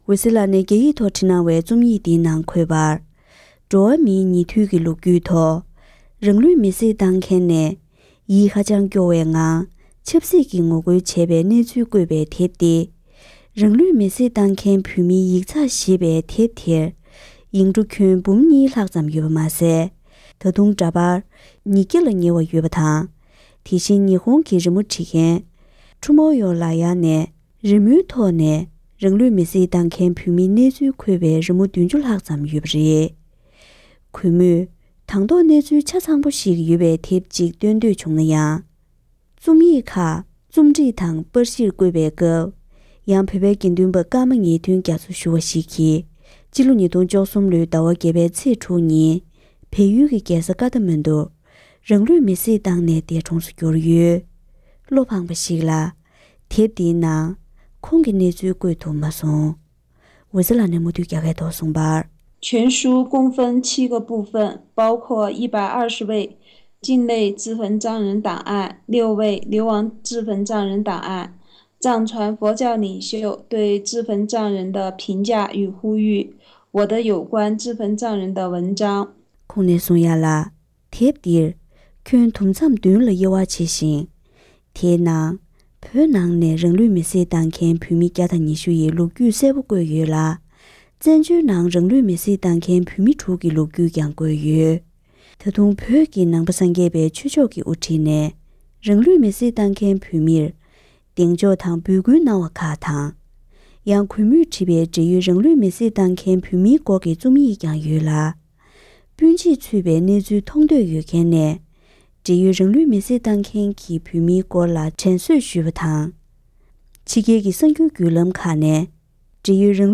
ཕབ་བསྒྱུར་སྙན་སྒྲོན་གནང་གི་རེད།།